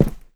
step5.wav